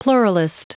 Prononciation du mot pluralist en anglais (fichier audio)
Prononciation du mot : pluralist